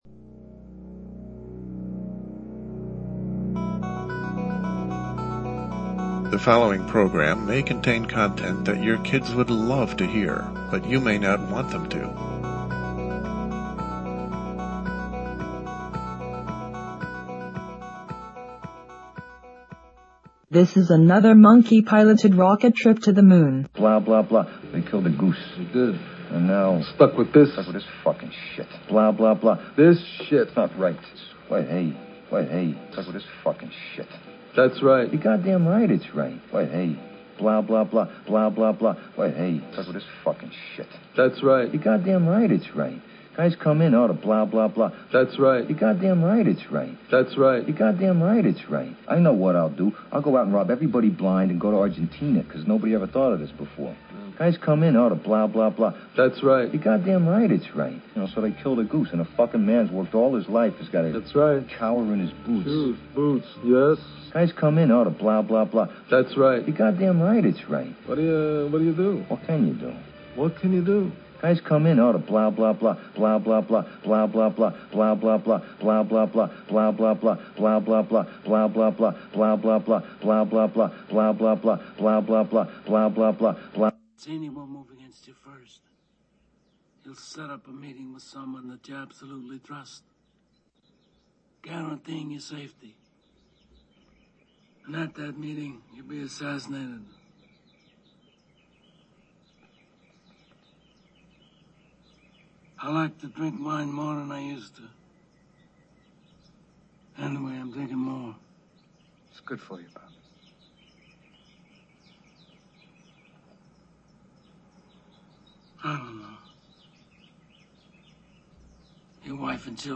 LIVE, Thursday, April 20 at 9 p.m. EST, search with me through the annals of our show, going back at least a decade, and explore how the landscape of internet radio has changed along with the Internet’s content related to broadcasting and the independent performer. This is not nostalgia, mind you, it is an honest look at the battlefield after the war has been all but lost.